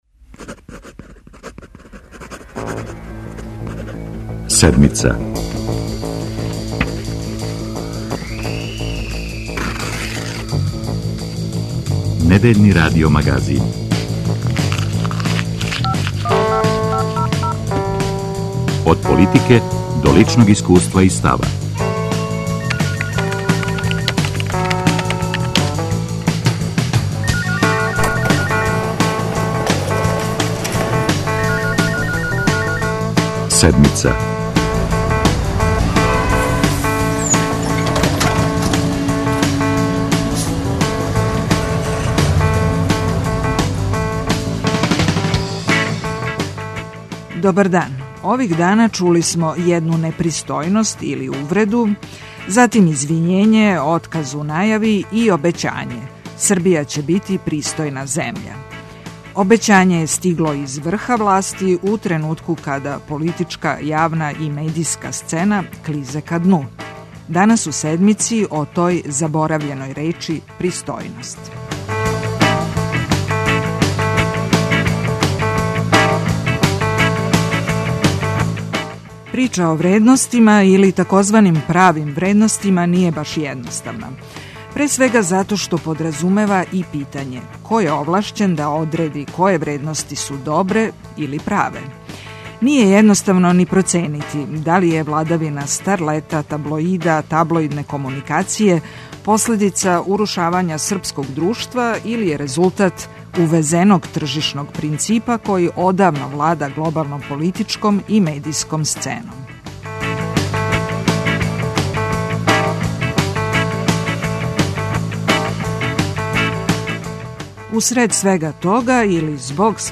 Гости емисије су Ана Софреновић, глумица, Александар Мандић, редитељ и Драгољуб Дража Петровић, новинар.